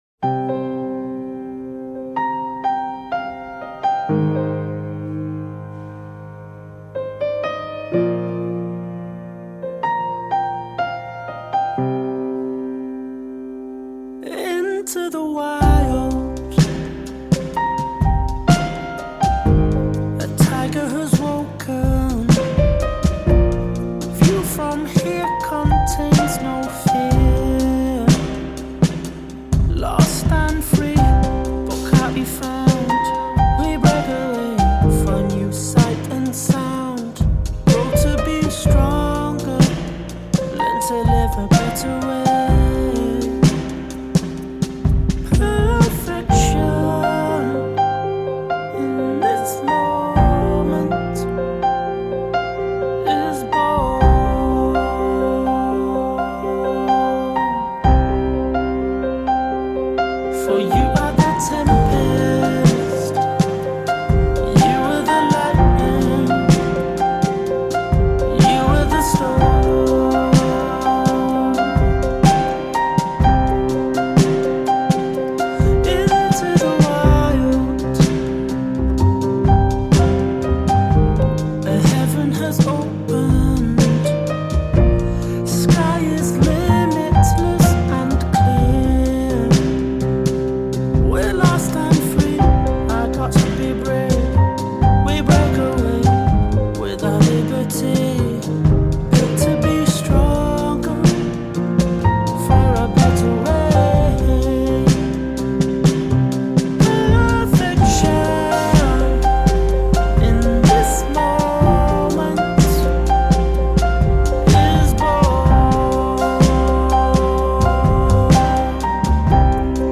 Piano Keys Writing Session Recording